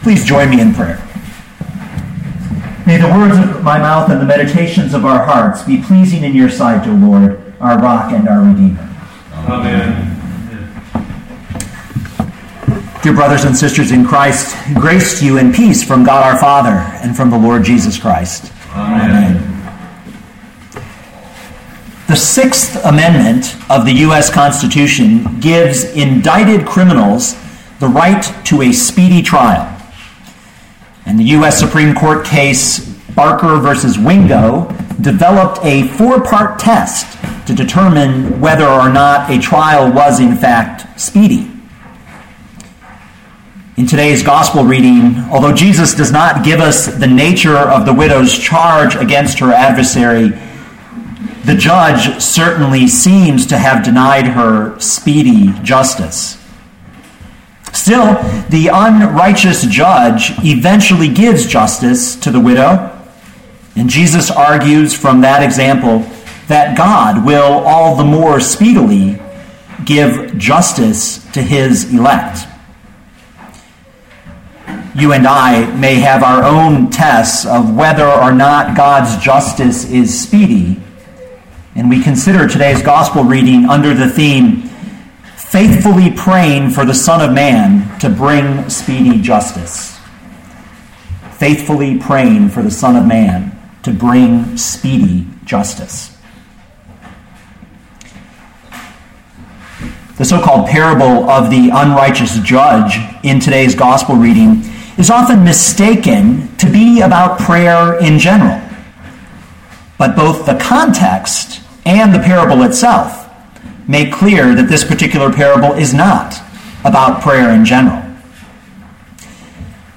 2013 Luke 18:1-8 Listen to the sermon with the player below, or, download the audio.